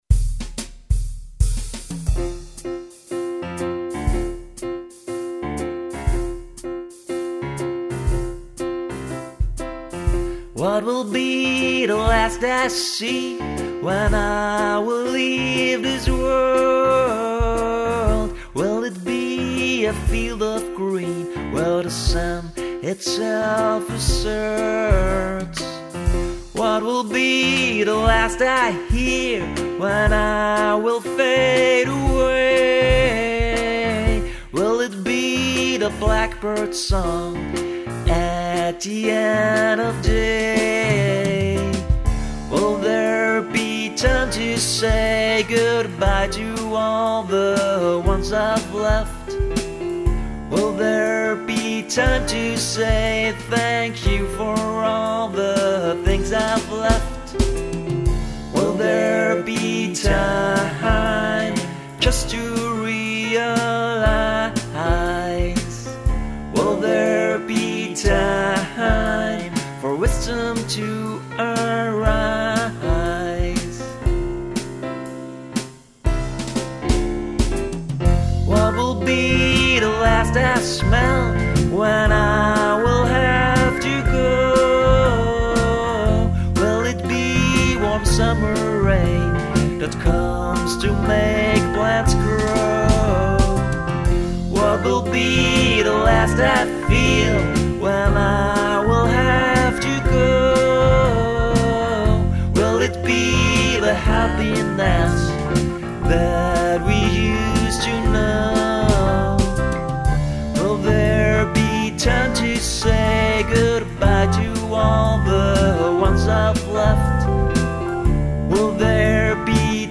vocals, piano, synth-organ, bass, drum-programming